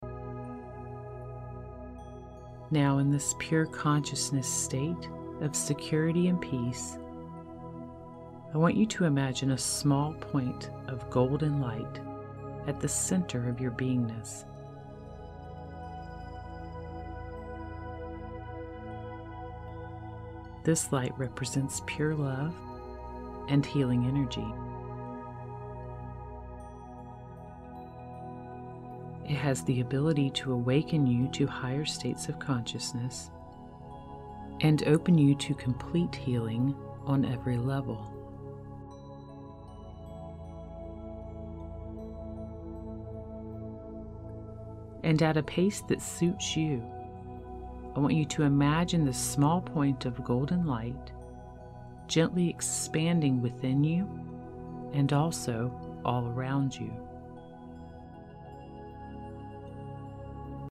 5th Dimension Light Body Activation Guided Meditation for Ascension